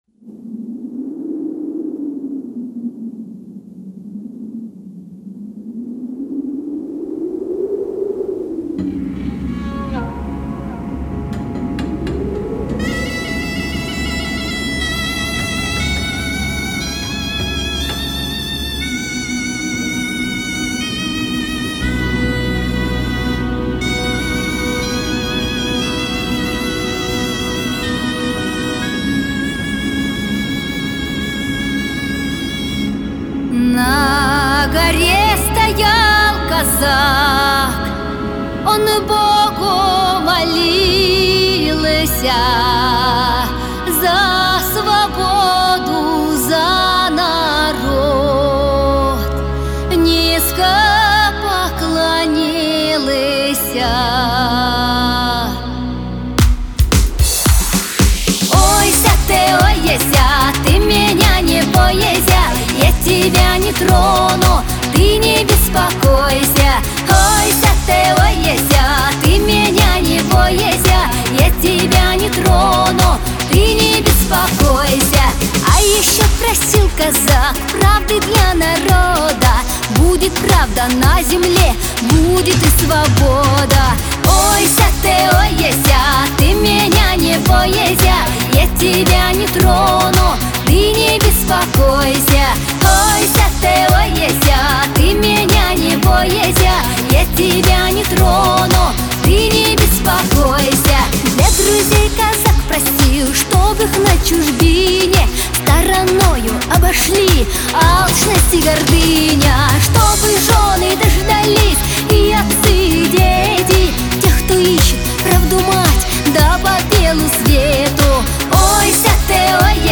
• Качество: Хорошее
• Жанр: Детские песни
народный мотив